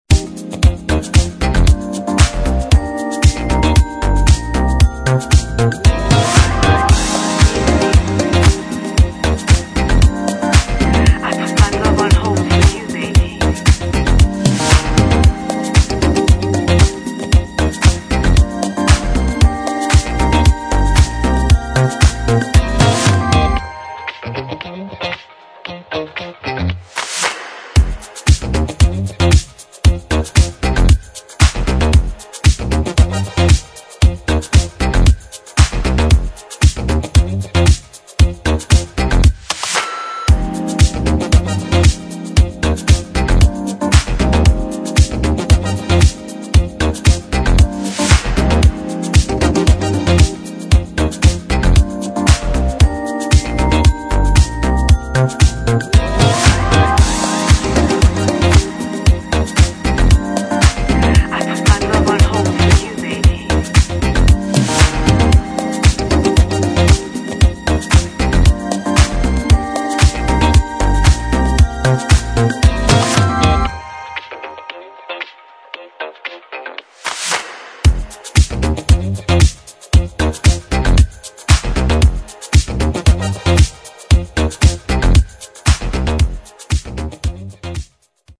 [ DISCO ]
Extended Instrumental